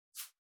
402,パーカーの音,衣服の音,衣類の音,サラッ,シャッ,スルッ,
効果音洋服関係